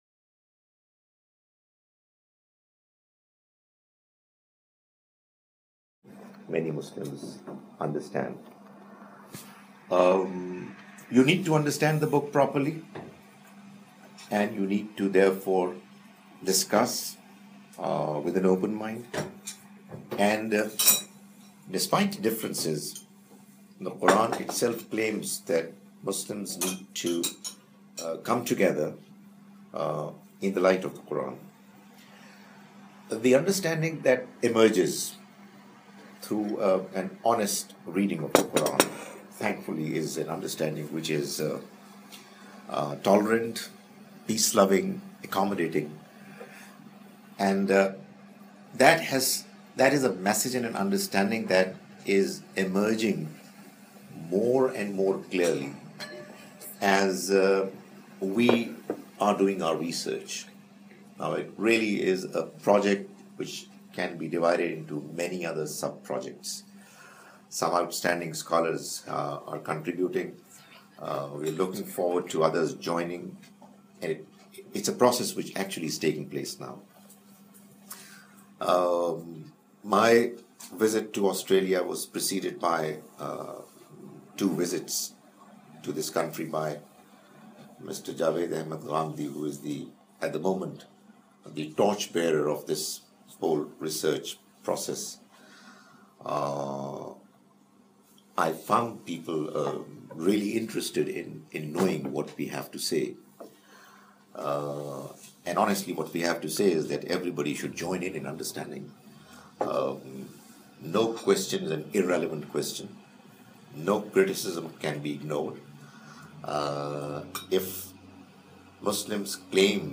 A Coversation
in converstion at Internation Centre for Muslims and Non-Muslims Understanding, University of South Australia